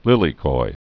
(lĭlē-koi) also li·li·ko'i (lēlē-kōē)